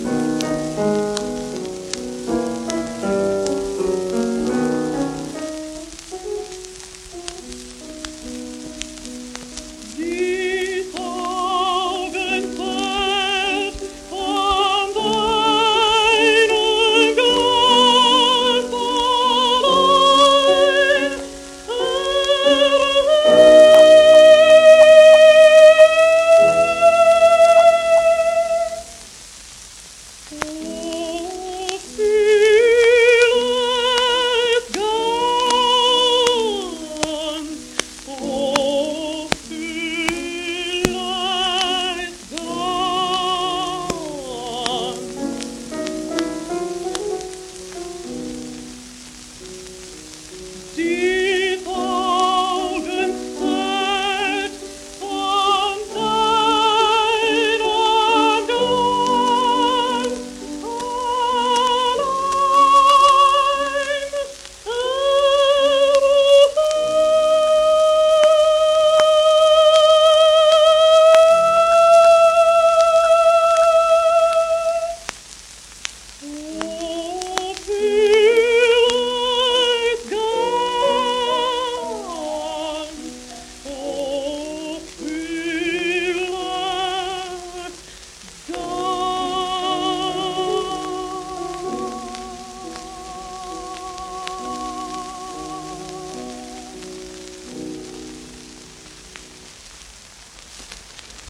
ジークリート・オネーギン(Alt:1889-1943)
オススメ盤ですが、キズ音あります。